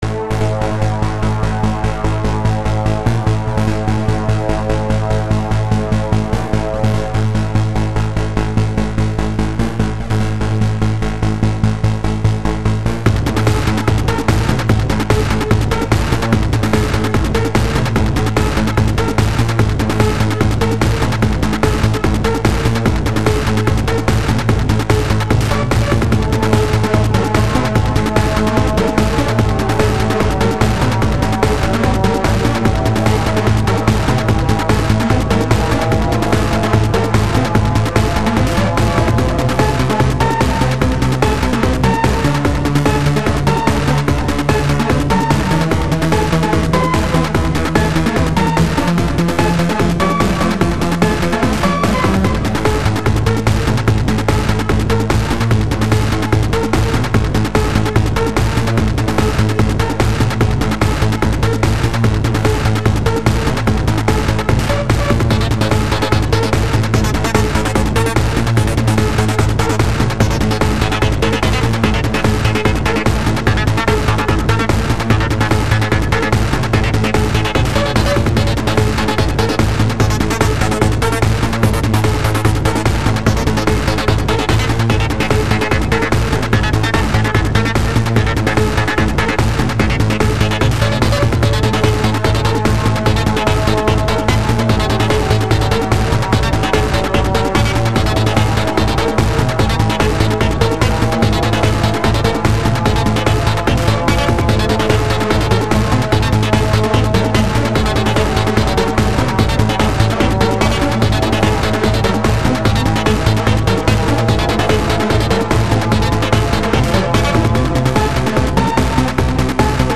It reminds me of hardstyle songs.